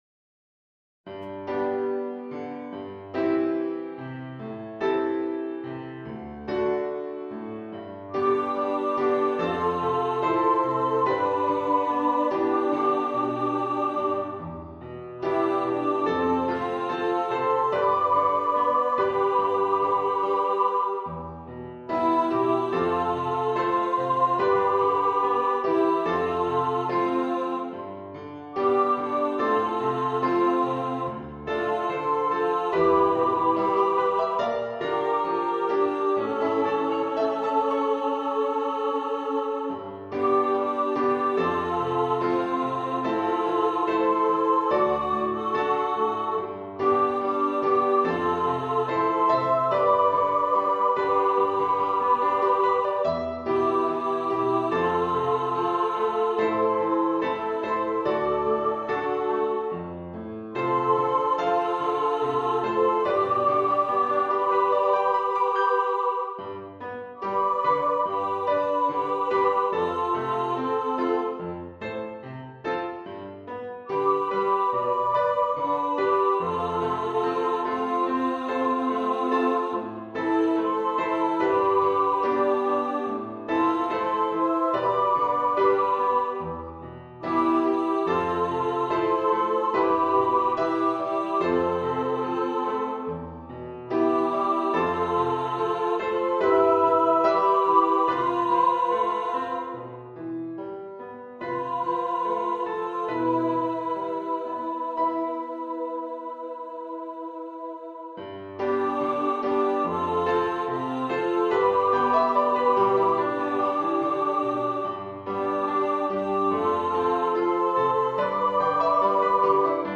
2-part Choir, MIDI
Instrumentation: 2-part Treble Choir and Piano